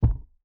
thud1.wav